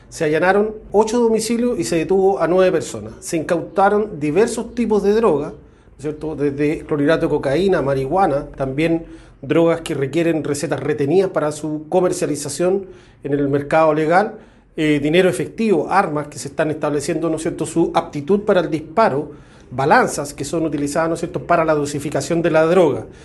En una investigación conjunta con la Fiscalía Local de Temuco, que se desarrolló por tres meses, Carabineros de la Sección OS-7 Araucanía, con apoyo de efectivos del GOPE, materializaron órdenes de entrada, registro e incautación para 8 domicilios en distintos sectores de la comuna de Padre Las Casas, buscando erradicar la venta de drogas y lograr la captura de sujetos con órdenes de detención vigentes, tal como lo detalló el jefe de Zona de Carabineros en La Araucanía, general Manuel Cifuentes.